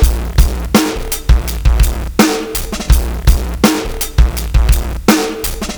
F_ck Santa Drum Break 83bpm.wav